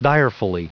Prononciation du mot direfully en anglais (fichier audio)
Prononciation du mot : direfully